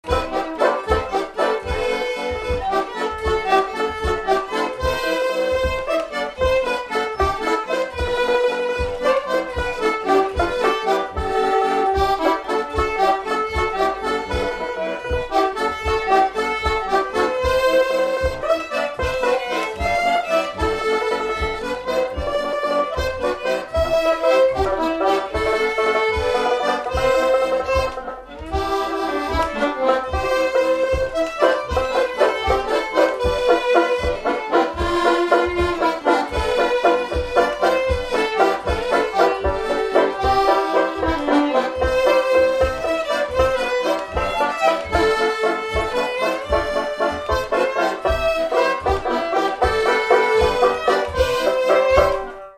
Mémoires et Patrimoines vivants - RaddO est une base de données d'archives iconographiques et sonores.
Instrumental
danse : valse
Pièce musicale inédite